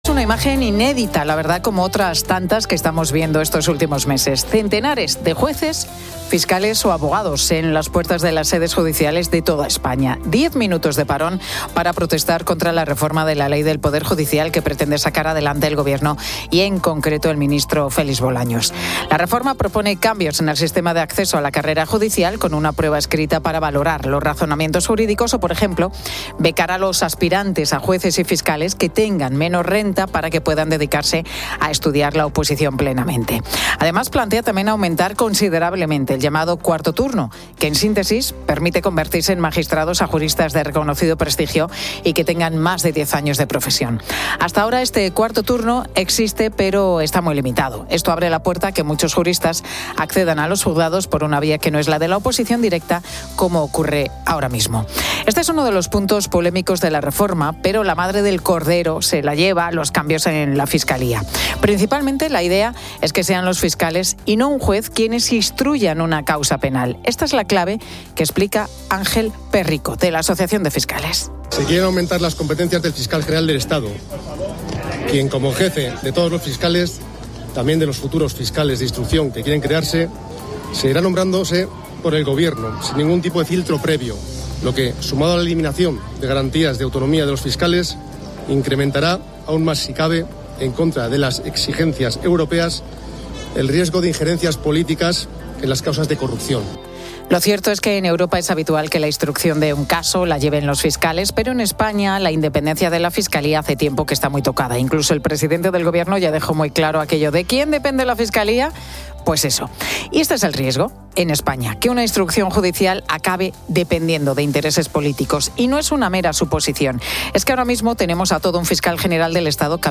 La Tarde 16:00H | 11 JUN 2025 | La Tarde Pilar García Muñiz se desplaza hasta Los Ángeles para conocer cómo se ha vivido la primera noche con toque de queda.